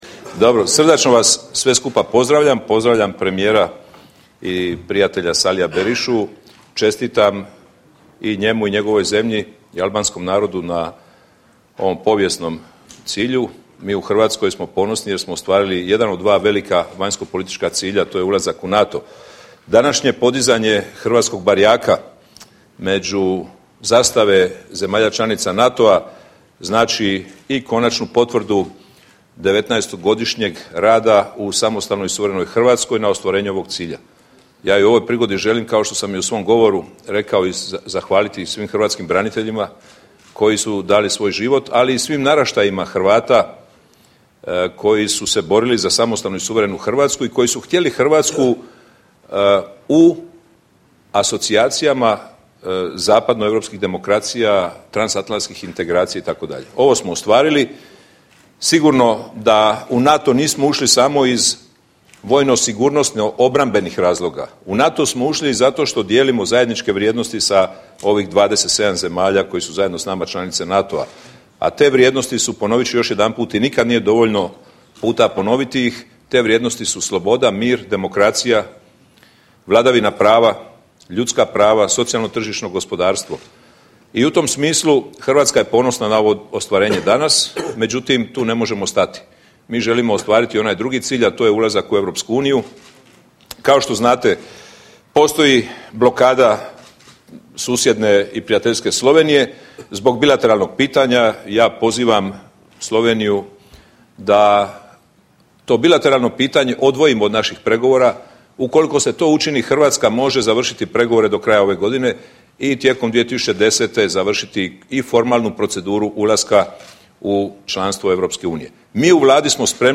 Joint press briefing by the Prime Ministers of Albania and Croatia